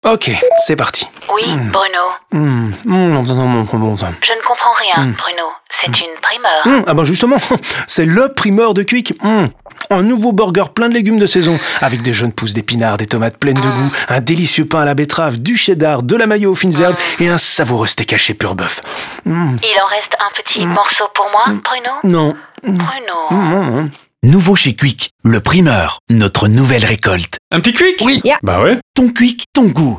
Happiness a non seulement imaginé le concept du burger, mais a aussi développé un spot TV, un spot radio dans la continuité de la saga avec Bruno, des affichages 2/8/10/20 m2, du matériel PLV et des formats pour le digital et les réseaux sociaux.
Le Primeur_audio_spot.wav